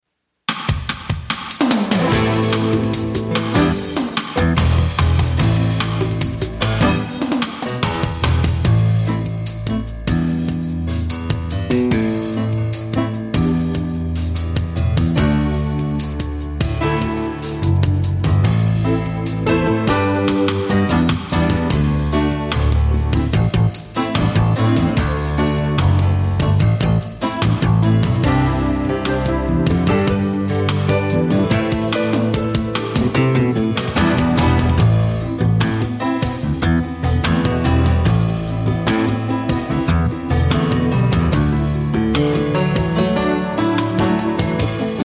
Listen to a sample of the instrumental.